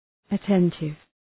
{ə’tentıv}